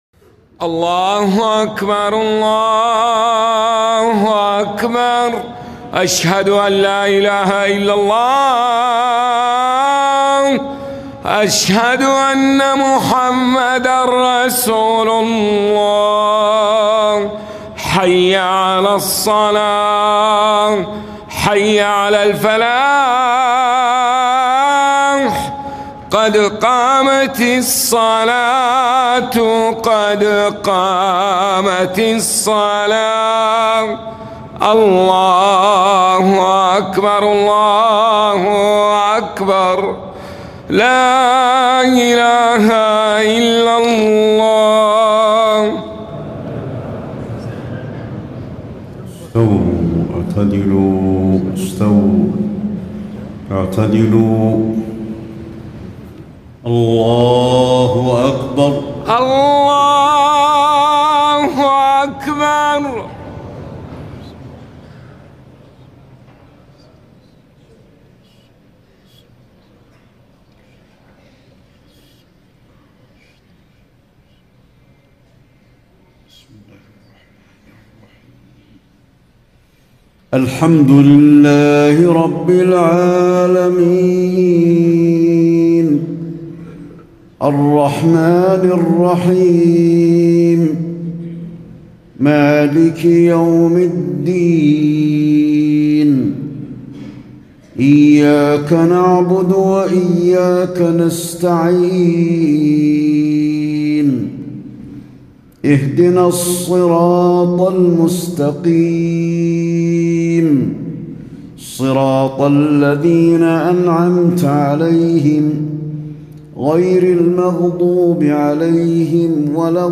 صلاة العشاء 7-2-1435 سورتي البلد و الشمس > 1435 🕌 > الفروض - تلاوات الحرمين